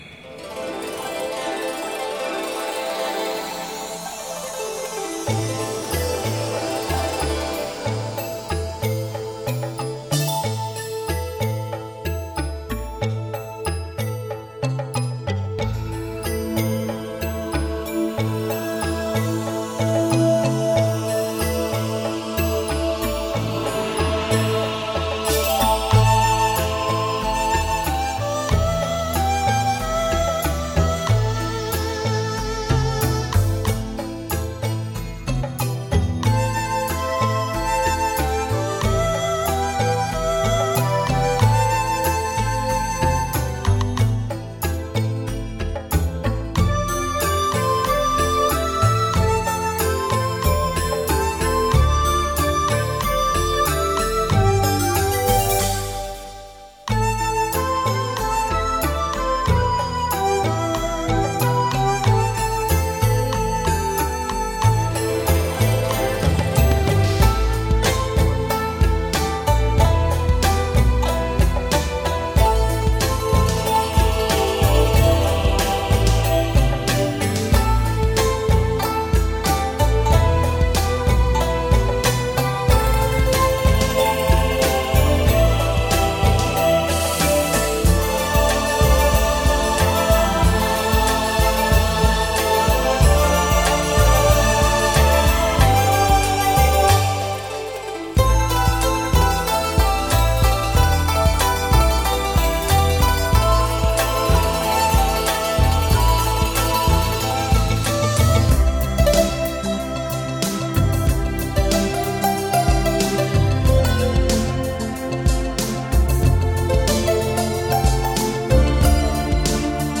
这张唱片的编曲和节奏最为舒缓松弛，各种民族乐器编制起一个宽大的音响空间。